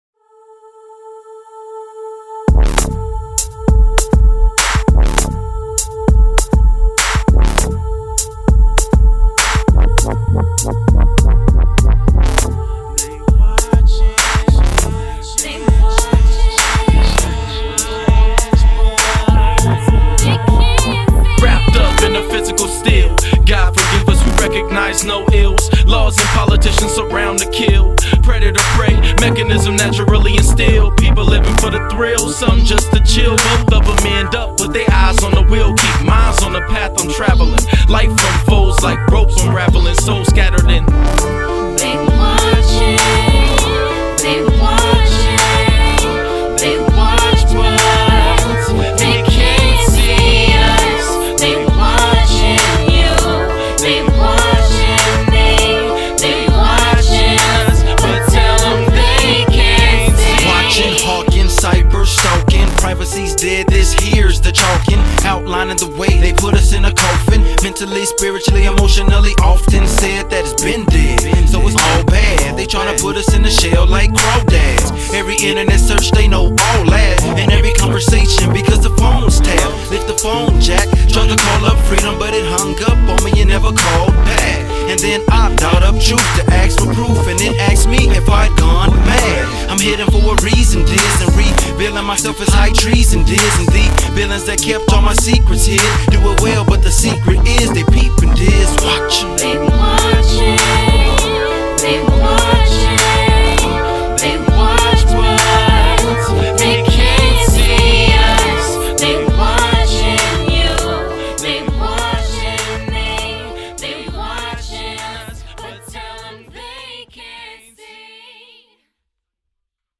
Recorded at Ground Zero Studios & Seattle ChopShop